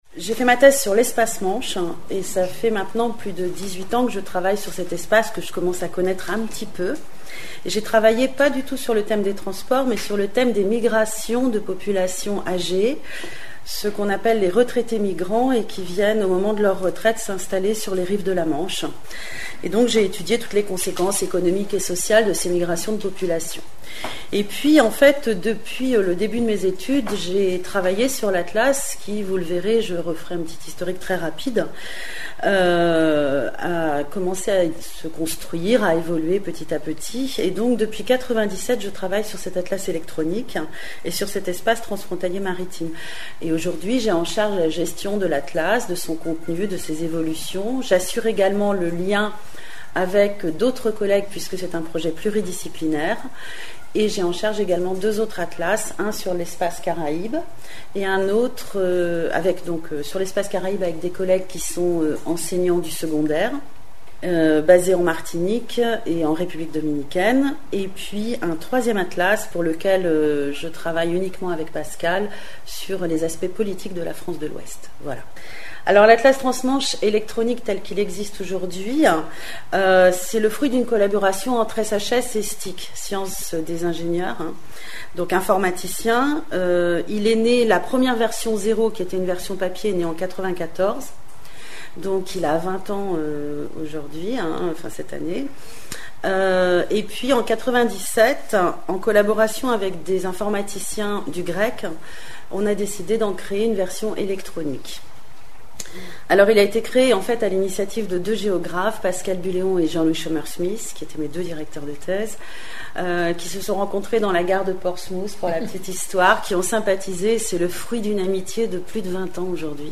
Cet exposé se propose de faire le point sur l'état et l'organisation du trafic et du commerce maritime dans cette petite mer franco-britannique.